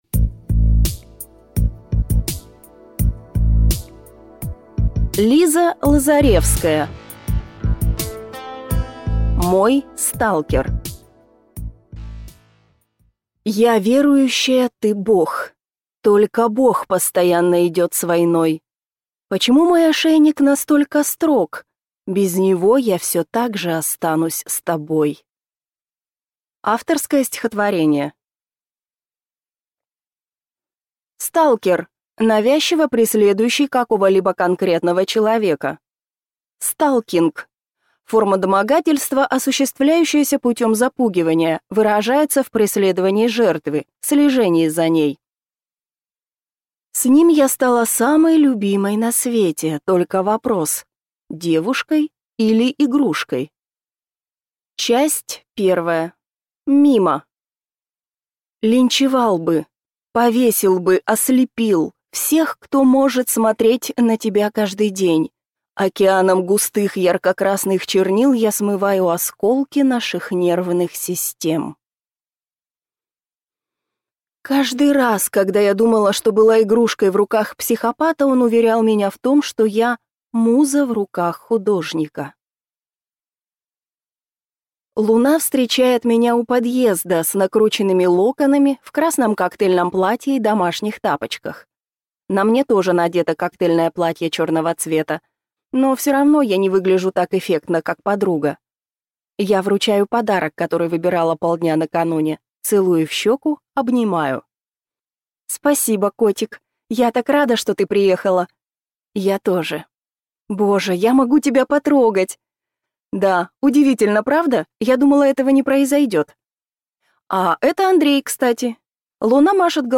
Аудиокнига Мой сталкер | Библиотека аудиокниг
Прослушать и бесплатно скачать фрагмент аудиокниги